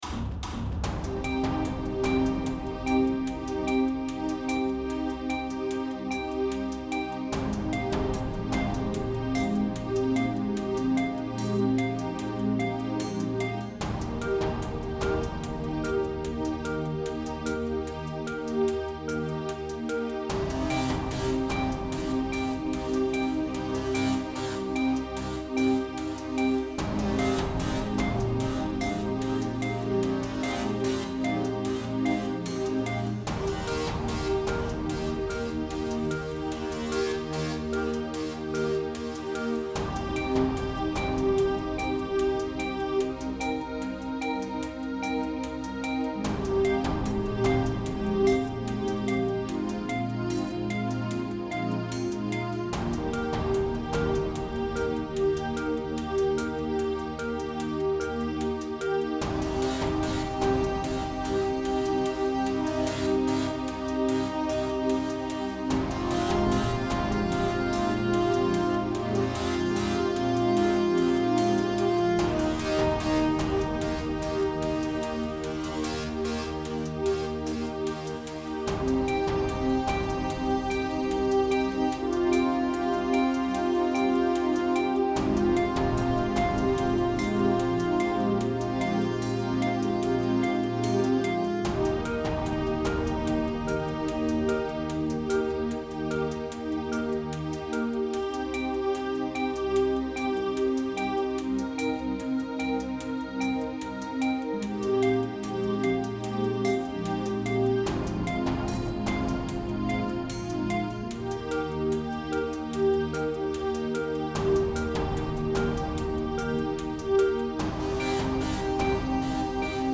Dark Instruments: Synthezieser pads, brass Genre